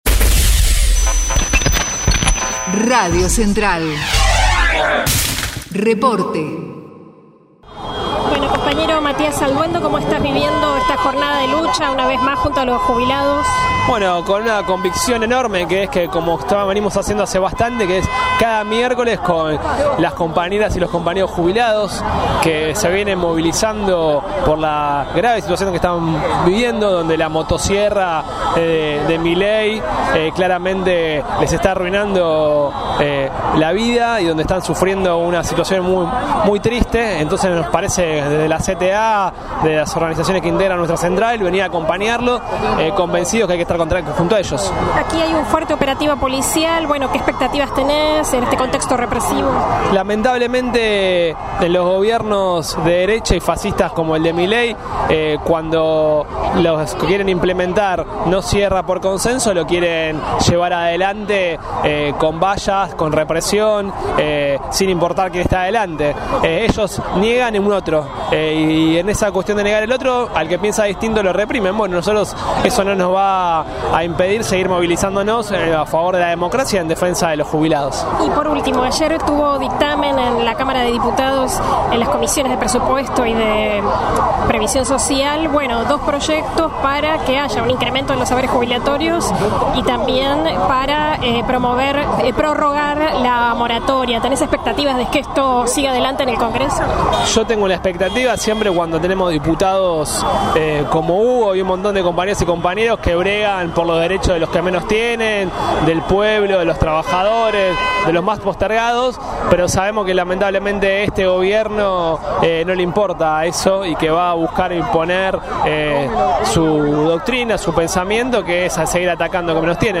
MARCHA DE JUBILADOS AL CONGRESO: Testimonios CTA